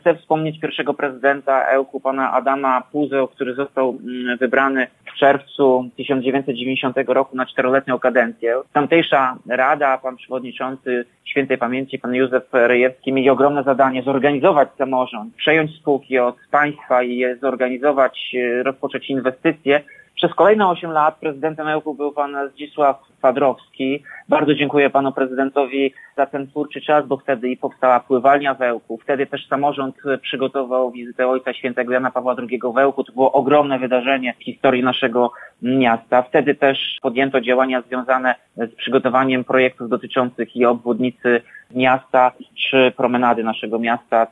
Wspomina prezydent miasta Tomasz Andrukiewicz, który włodarzem jest już od 14 lat.